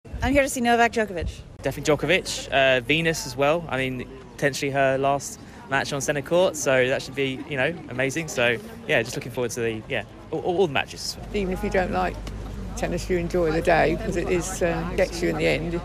Fans at Wimbledon.